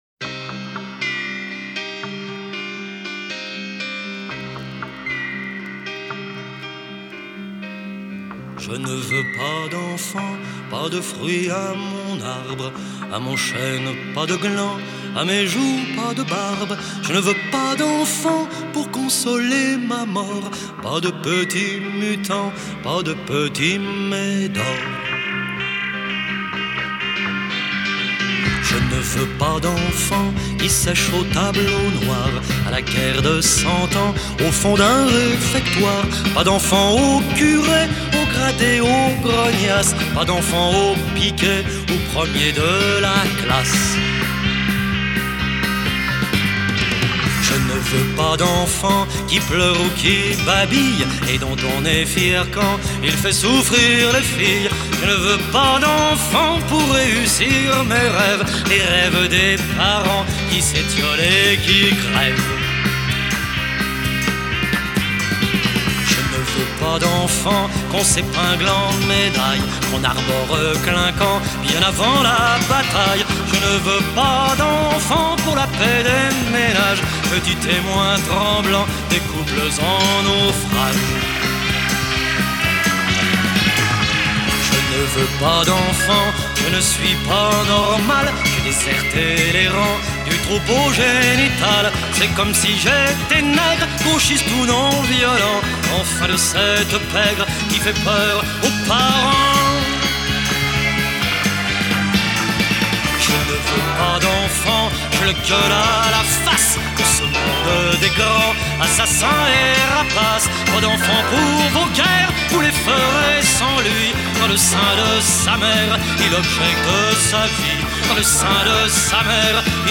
Genre: Franchouille.